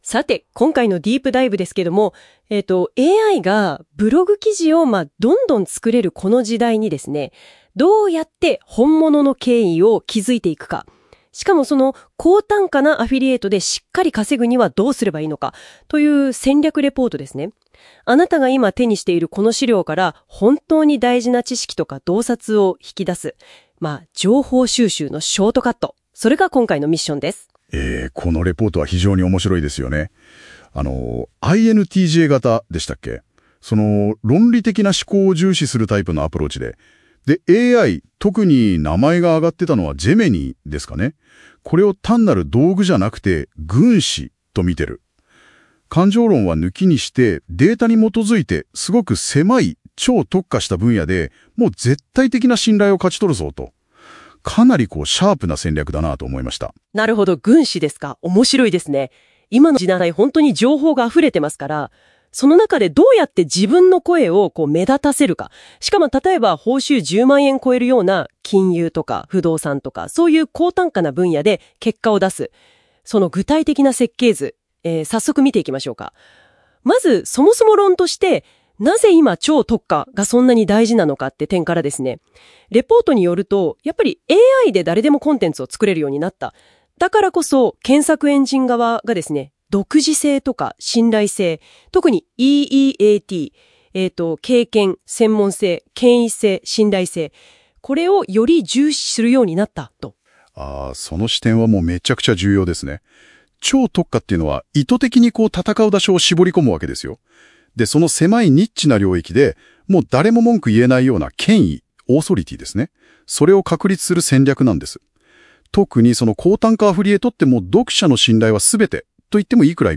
【音声解説】AI軍師を使いこなせ：高単価アフィリエイトで超特化ブログの権威性を築く戦略INTJ型分析レポート解説